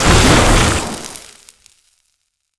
electric_lightning_blast_04.wav